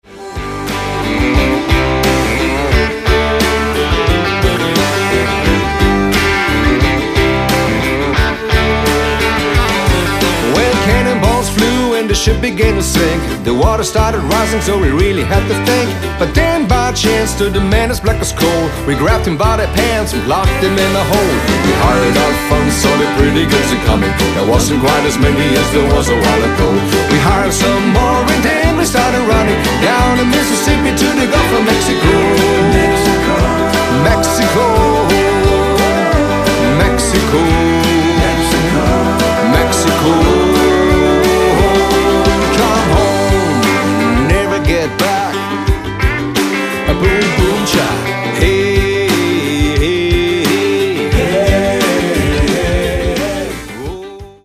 Stil / Art: Country, Bluegrass, Country-Rock
Aufgenommen: Hilltop Studios Nashville, U.S.A.